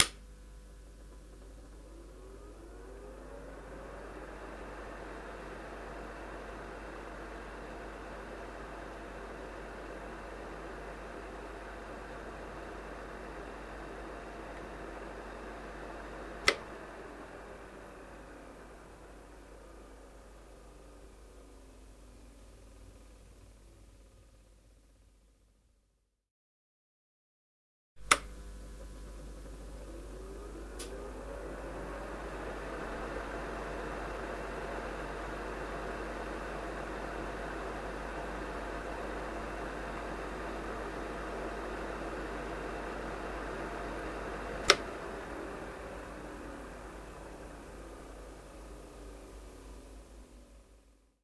На этой странице собраны звуки микроскопа — от щелчков регулировки до фонового гула при работе.
Звук работы электронного микроскопа (включение, выключение) (00:51)